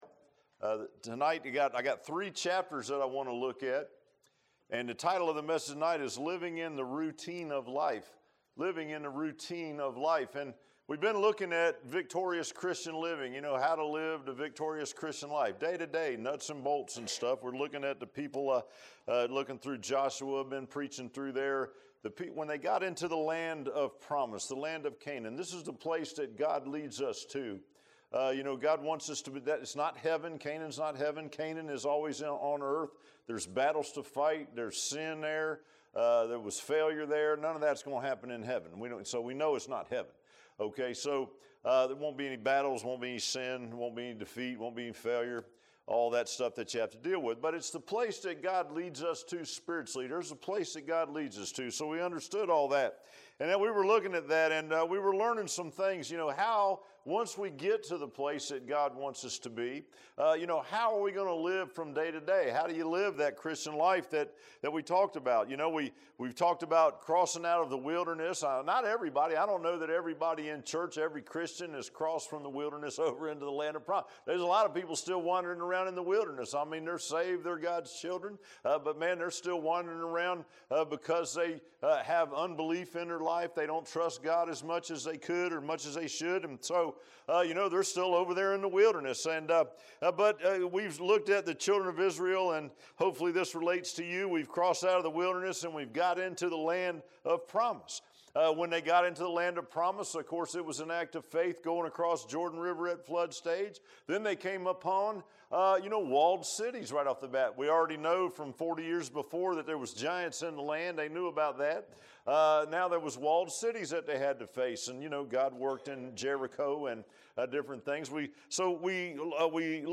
Joshua 22 Service Type: Sunday PM « Unexpected Things The Tabernacle